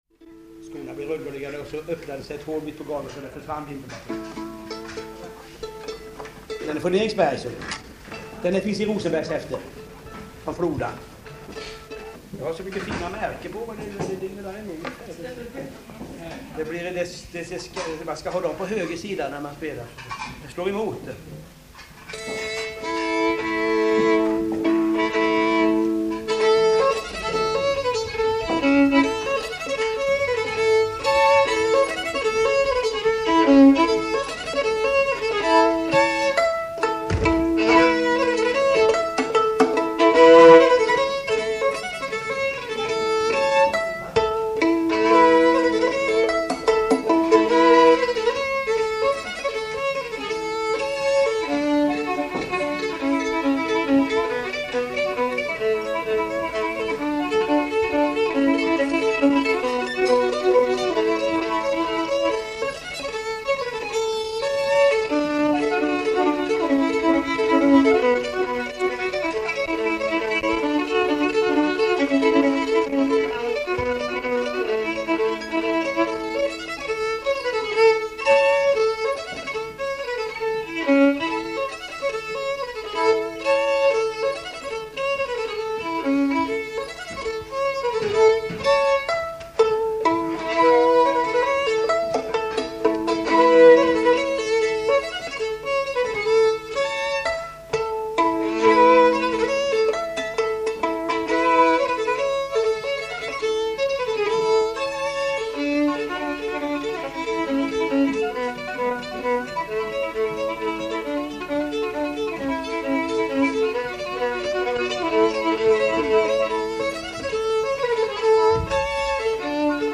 näckpolskor
Södertälje Spelmanslags årsfest 1964.
Snabbt tempo och stötta stråk – precis som många av hans sagesmän. Luftigt och elegant!